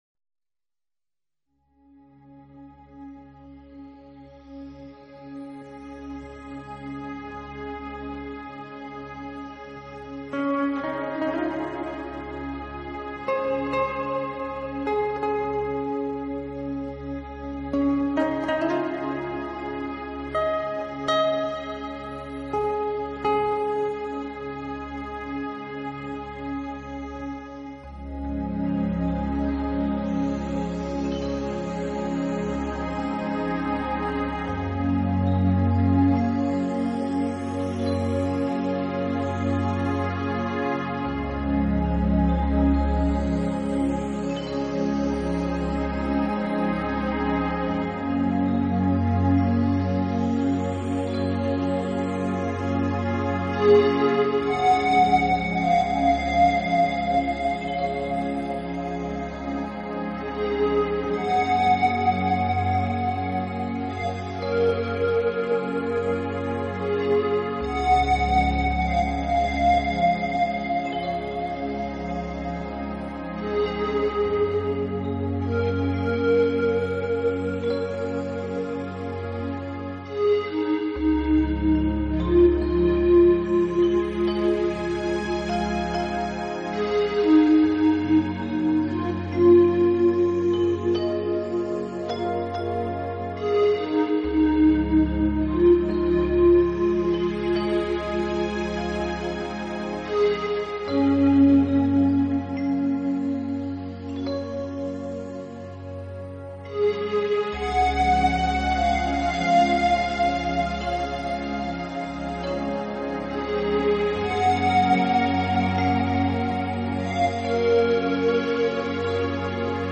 【新世纪纯音乐】
专辑语言：纯音乐